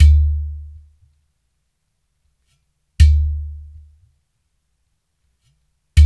80 -UDUS 06.wav